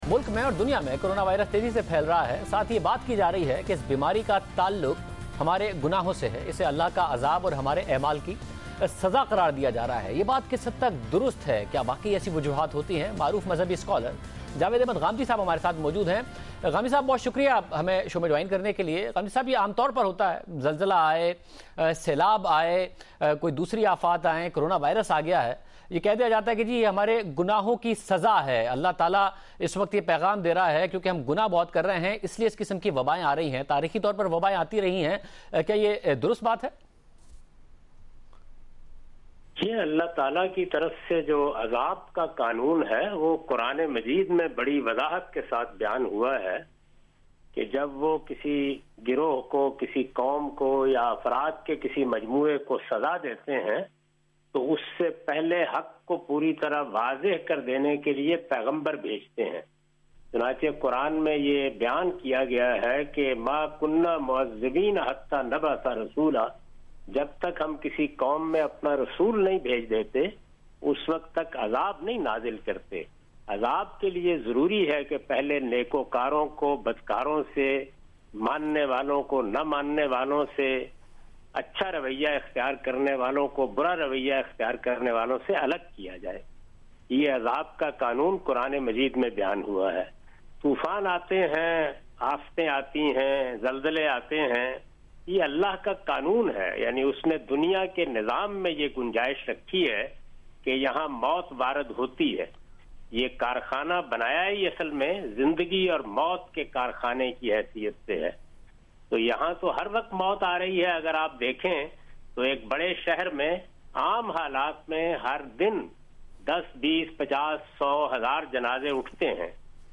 Javed Ahmad Ghamidi answers some important question about corona virus in Program, "Aaj Shahzeb Khanzada Kay Sath" on Geo News April 28, 2020.
جیو نیوز کے اس پروگرام میں جناب جاوید احمد صاحب غامدی کورونا وائرس سے متعلق کچھ اہم سوالات کا جواب دے رہے ہیں۔